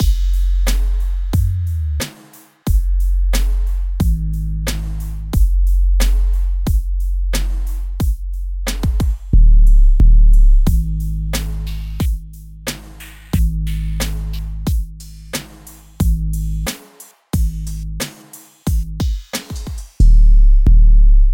旧学校 鼓声循环16
描述：嘻哈风格
Tag: 90 bpm Hip Hop Loops Drum Loops 3.59 MB wav Key : Unknown